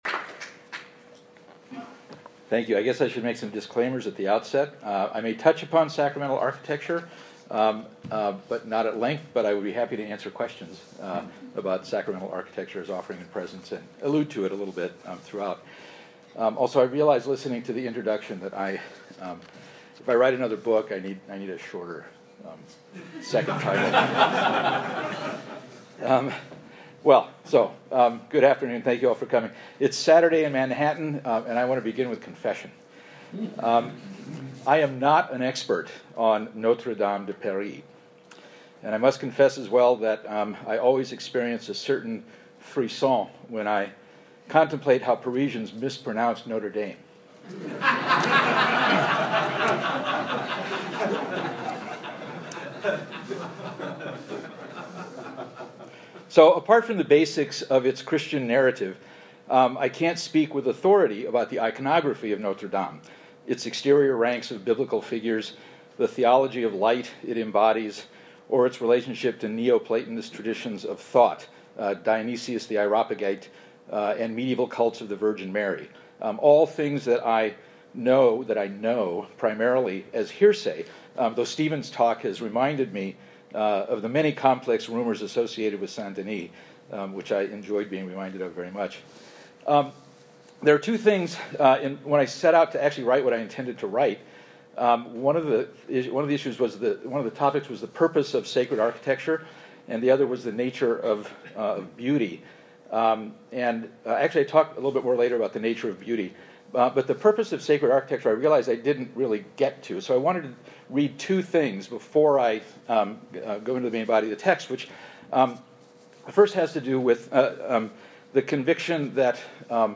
This lecture was given at New York University on November 16, 2019.